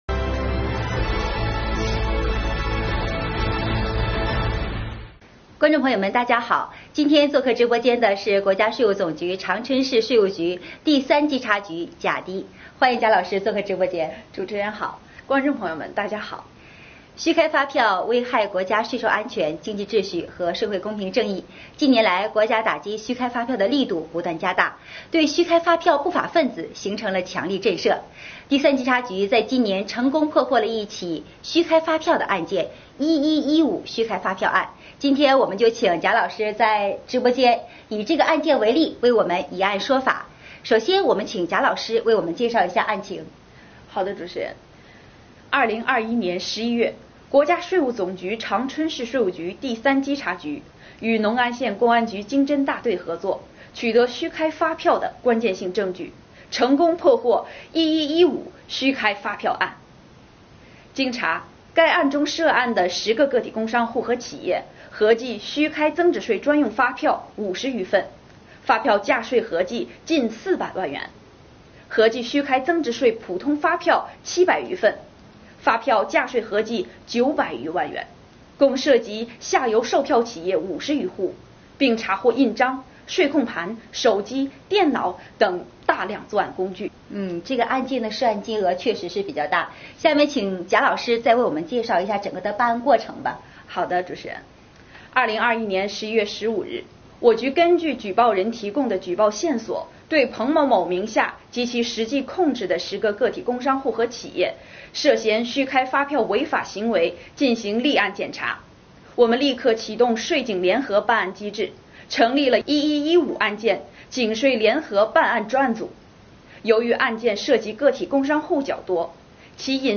长春市税务局“以案说法”长春市税务局第三稽查局破获“11.15”虚开发票案直播回放